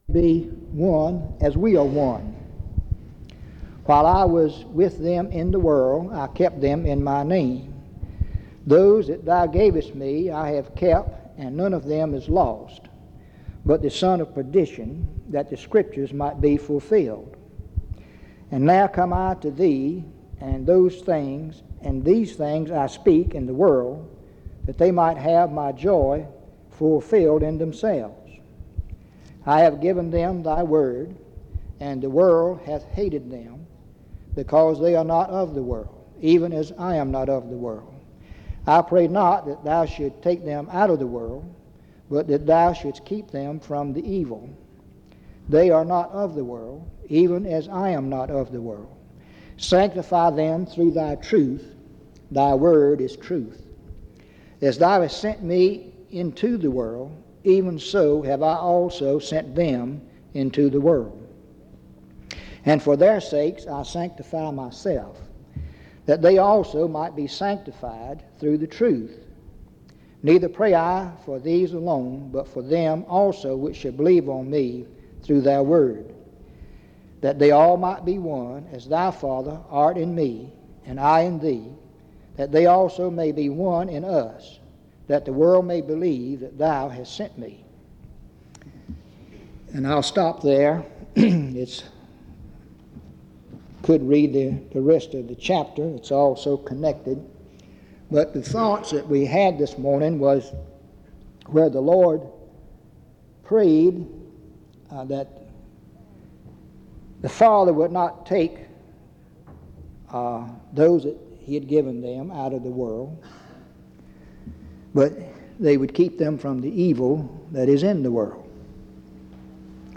In Collection: Reidsville/Lindsey Street Primitive Baptist Church audio recordings Thumbnail Titolo Data caricata Visibilità Azioni PBHLA-ACC.001_023-A-01.wav 2026-02-12 Scaricare PBHLA-ACC.001_023-B-01.wav 2026-02-12 Scaricare